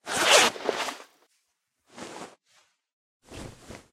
liz_outfit_equip.ogg